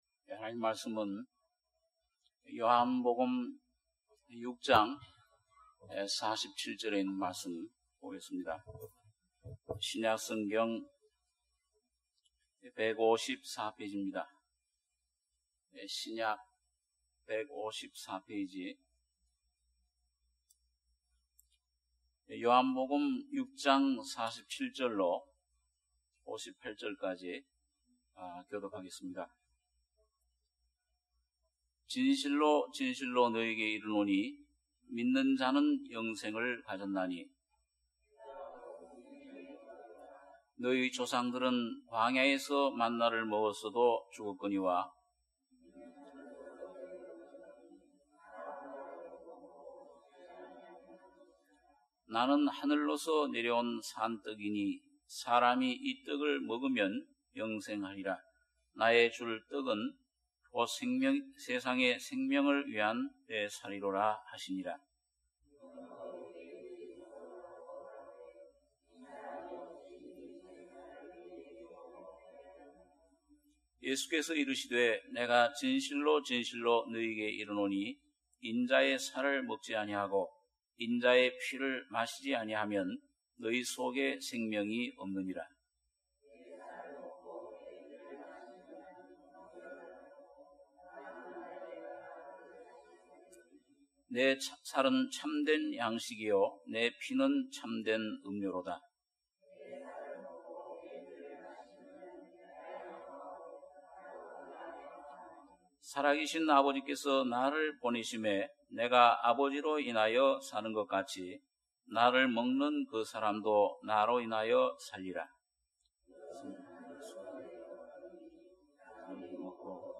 주일예배 - 요한복음 6장 47절~58절